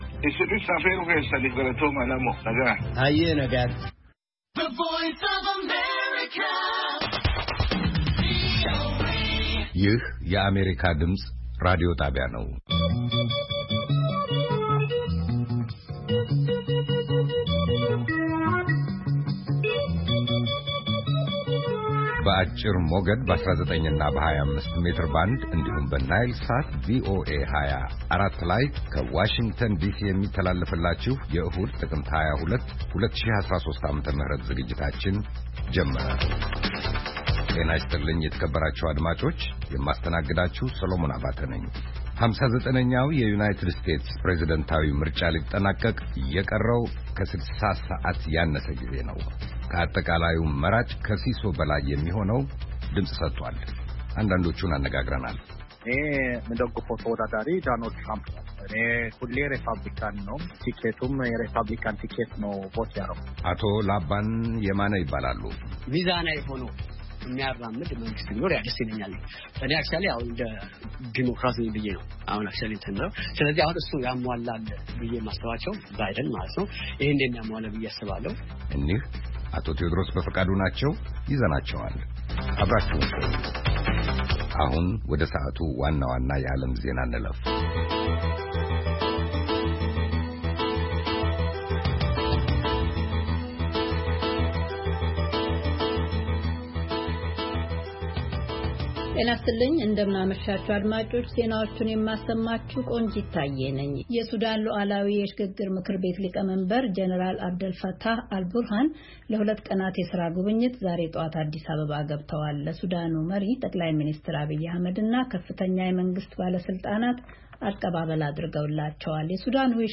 ዕሁድ፡- ከምሽቱ ሦስት ሰዓት የአማርኛ ዜና